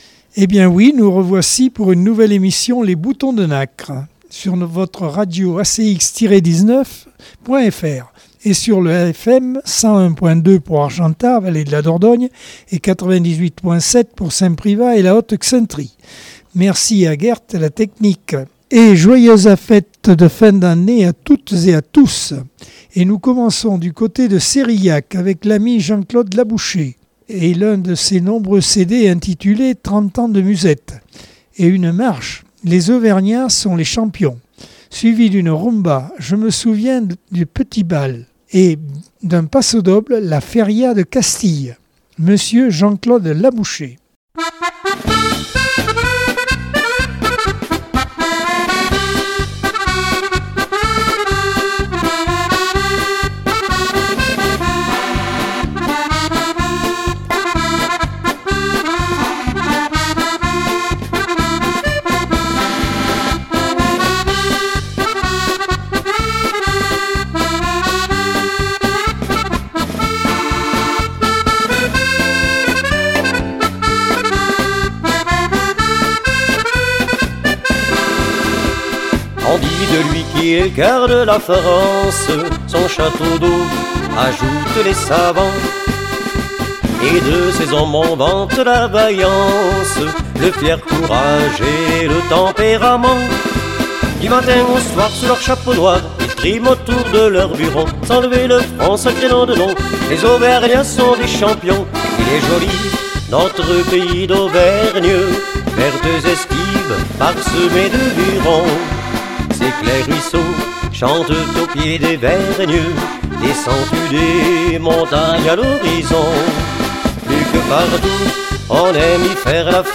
Accordeon 2022 sem 51 bloc 1 - ACX Vallée de la Dordogne